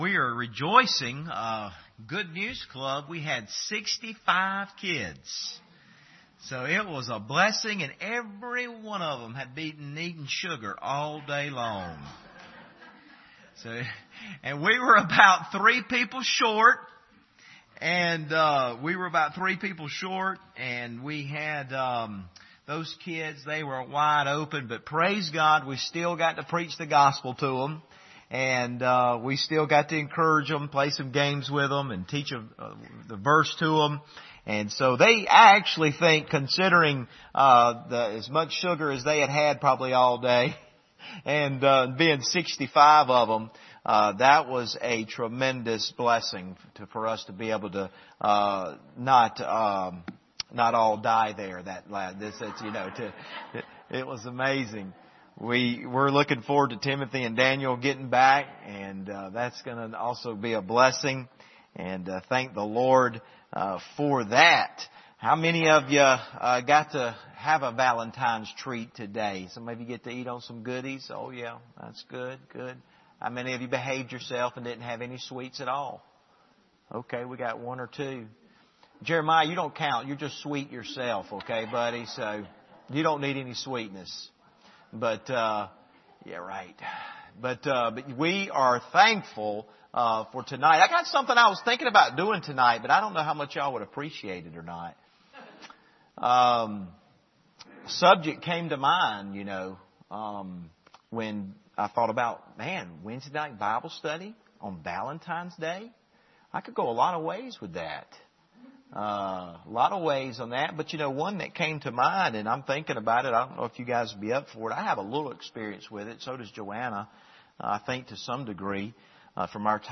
John 21:12-17 Service Type: Wednesday Evening Bible Text